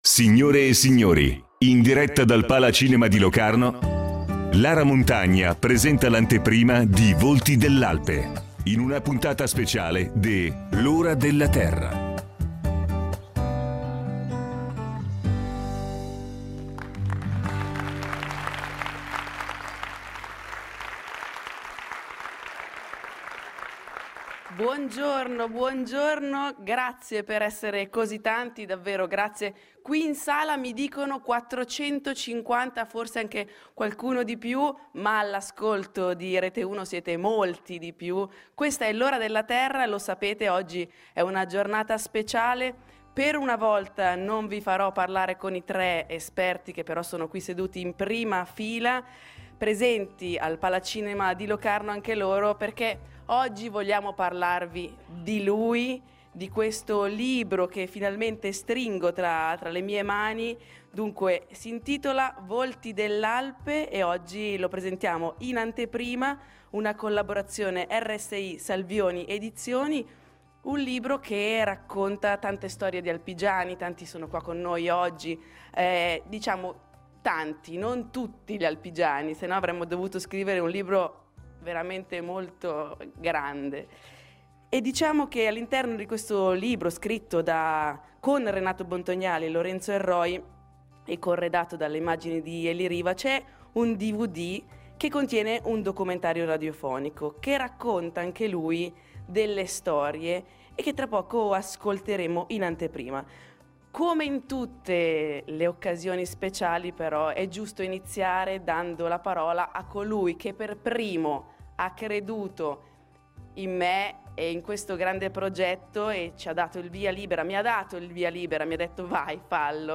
Volti dell'alpe, Palacinema Locarno